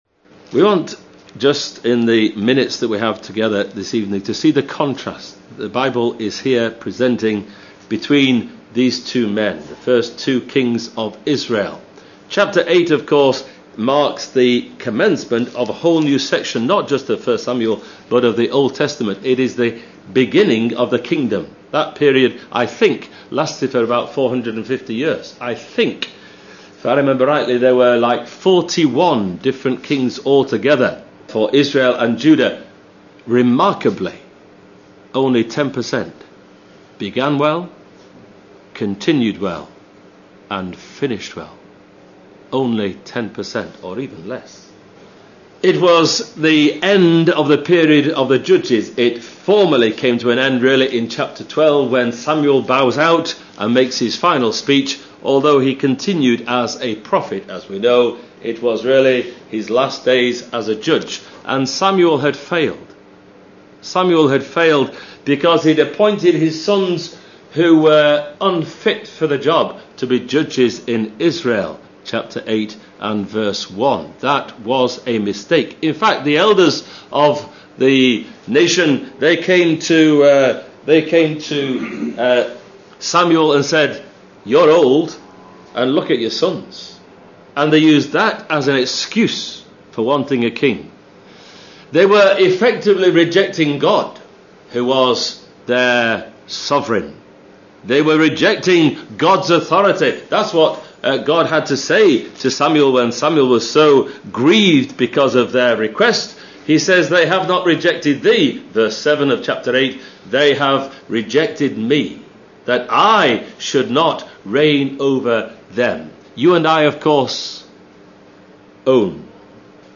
The dire and dangerous situation David faced was a time of “growth in his soul”. Such are the Lord’s designs with us when He brings us into difficulties and trial (Message preached 25th Jan 2015)